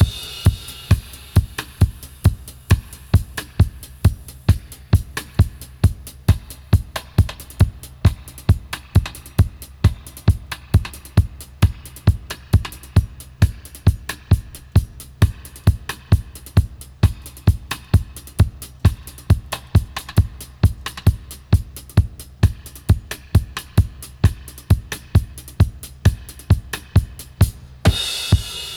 134-FX-02.wav